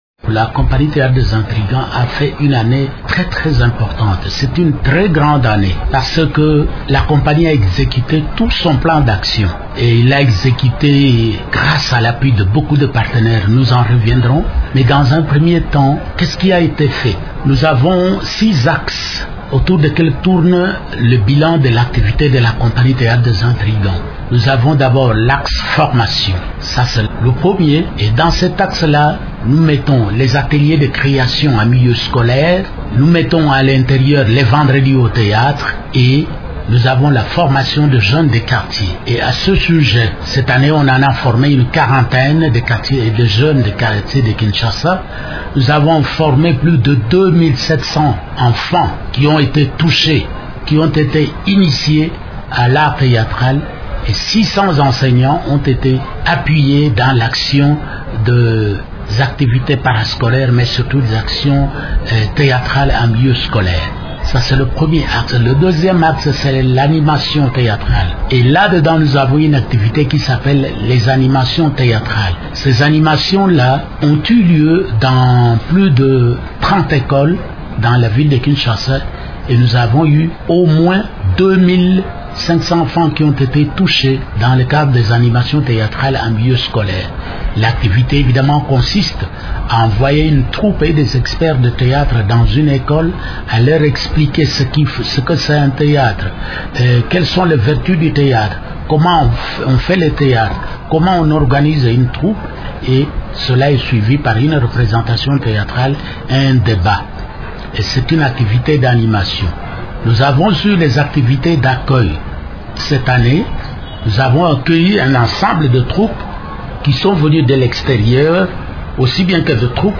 Dans une interview accordée le 14 janvier à Radio Okapi